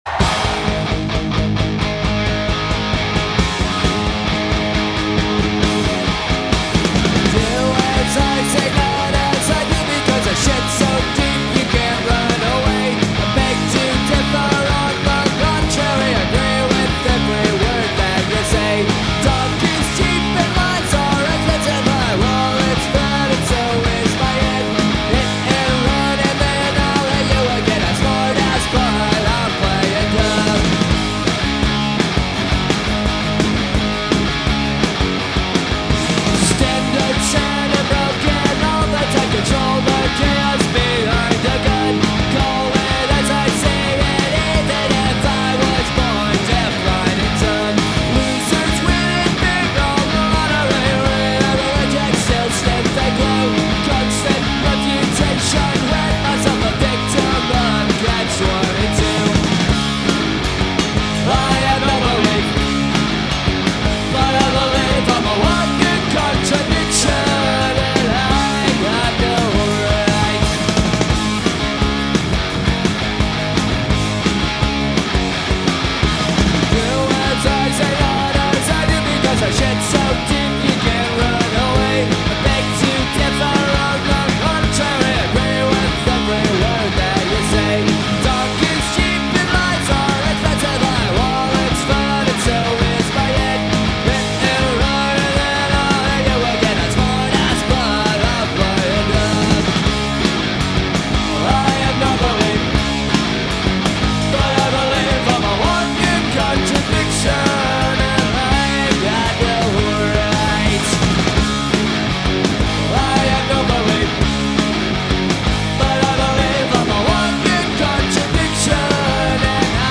Live '96 Prague